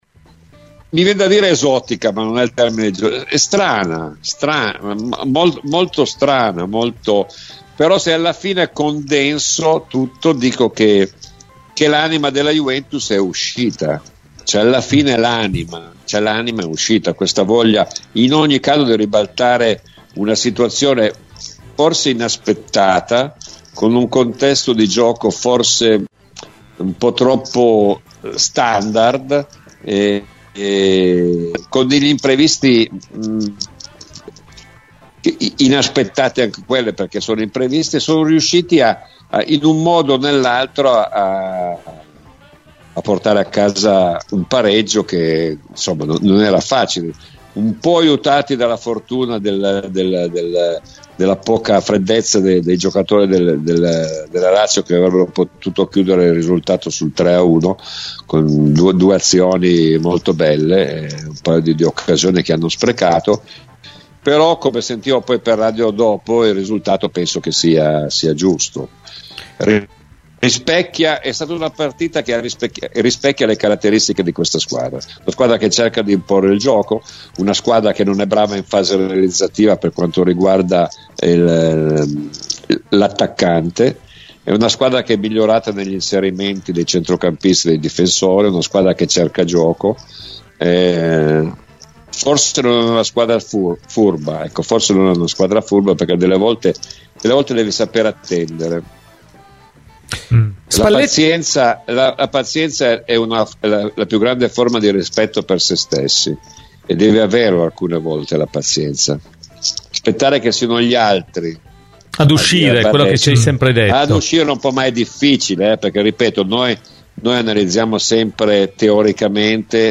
Nel corso di "Cose di Calcio" su Radio Bianconera, Domenico Marocchino ha analizzato il pareggio tra Juventus e Lazio nella serata di ieri: “È stata una partita molto strana, ma se alla fine condenso tutto, dico che l'anima della Juventus è uscita, questa voglia di ribaltare una situazione forse inaspettata con un contesto di gioco forse un po' troppo standard e con qualche imprevisto.